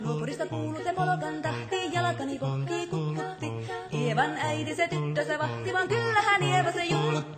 Polka
Category: Sound FX   Right: Personal